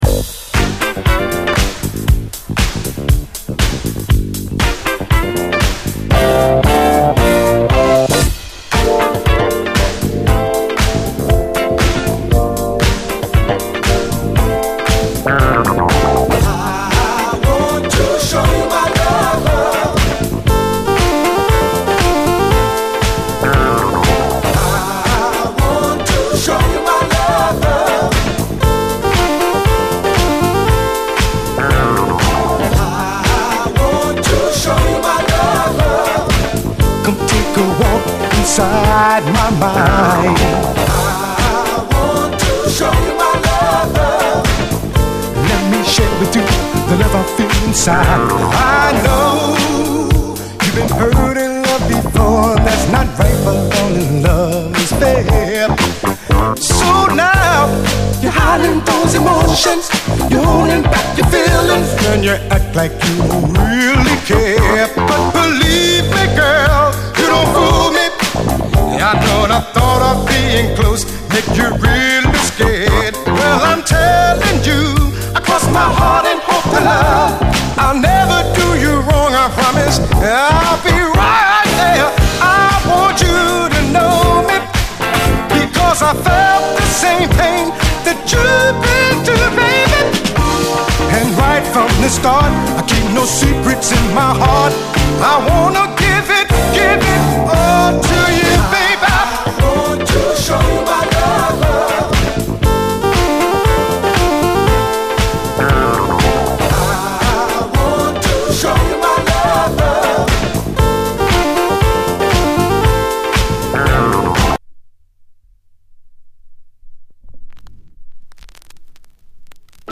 SOUL, 70's～ SOUL, DISCO, 7INCH
最高80’Sモダン・ブギー！アーバンなシンセがギラリと光る、強力なブギー・サウンドのダンサー・チューン！